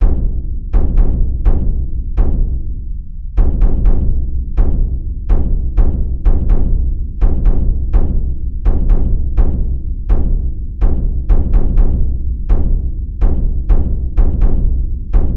描述：在低音的深处，心和手稳定地移动以保持节奏。
Tag: 125 bpm Cinematic Loops Drum Loops 2.58 MB wav Key : D